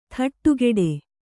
♪ thaṭṭugeḍe